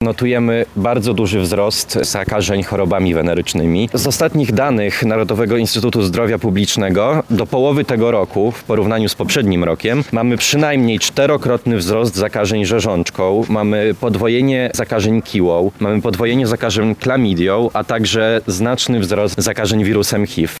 Federacja Młodych Socjaldemokratów zorganizowała w Lublinie happening przed biurem ministra edukacji i nauki Przemysła Czarnka.